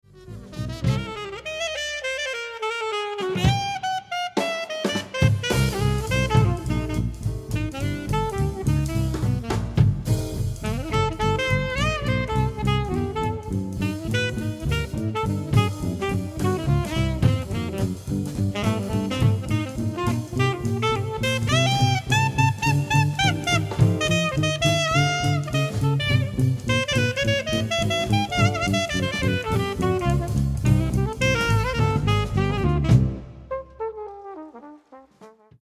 Clarinet and alto & tenor saxes
Alto-Sax